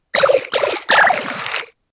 Fichier:Cri 0705 XY.ogg
contributions)Televersement cris 6G.